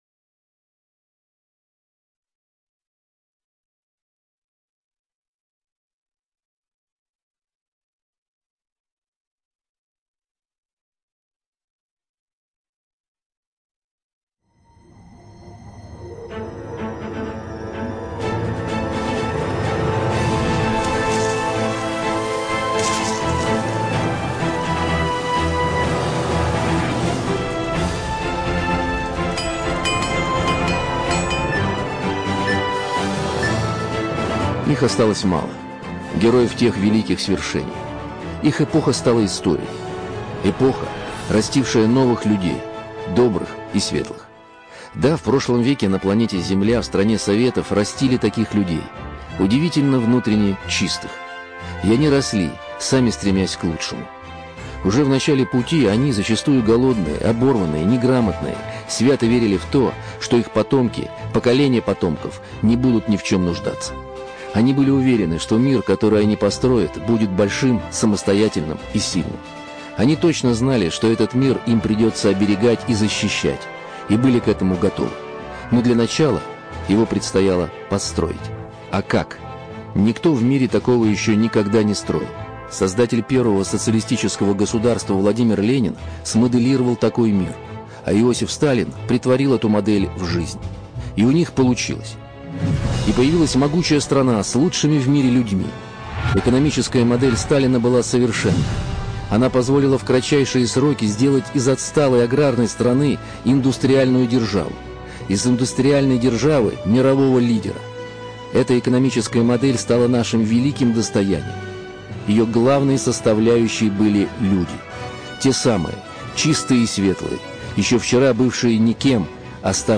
Документальный фильм «МОДЕЛЬ СТАЛИНА» телеканала Красная линия, состоящий из 4 частей: